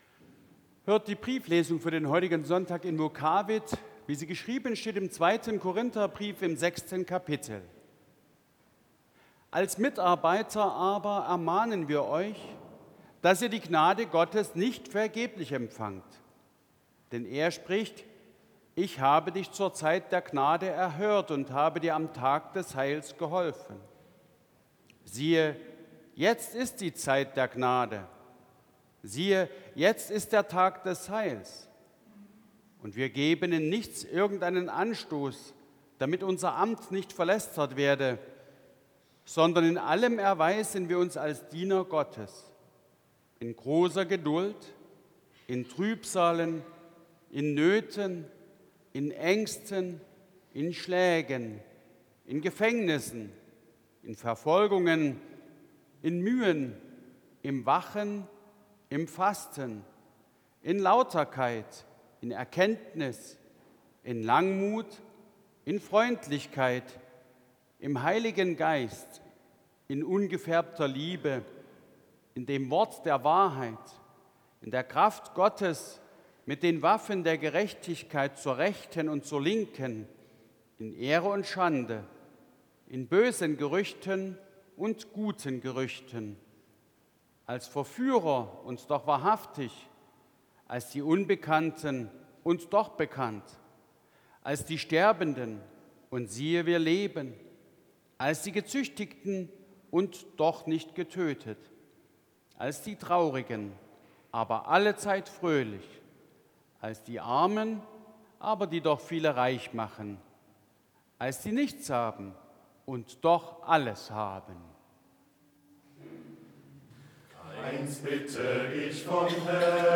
Lesung aus 2.Kointher 6,1-10 Ev.-Luth.
Audiomitschnitt unseres Gottesdienstes am Sonntag Invokavit 2025.